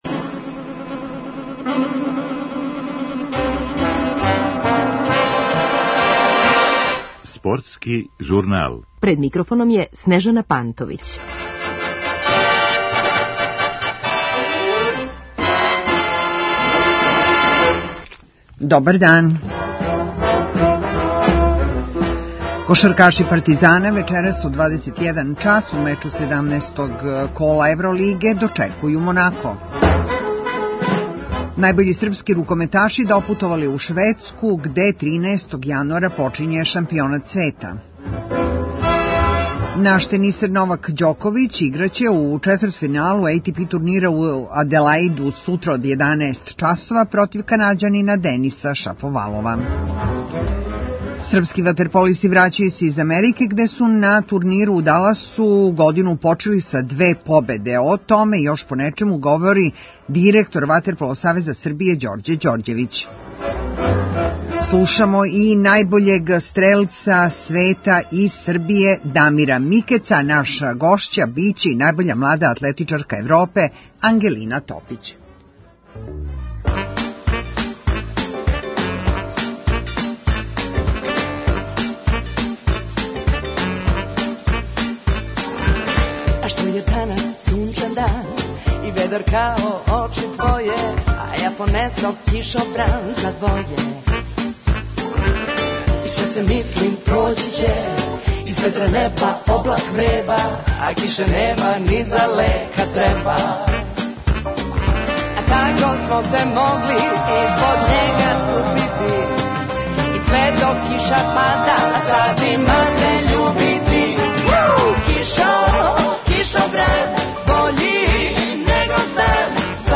У Журналу слушамо и најбољег стрелца на планети Дамира Микеца, и најбољу младу атлетичарку Европе, Ангелину Топић.